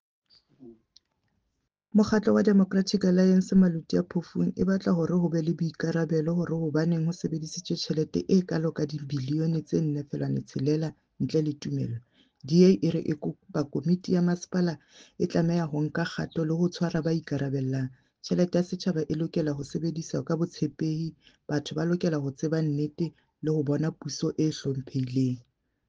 Sesotho soundbite by Cllr Ana Motaung.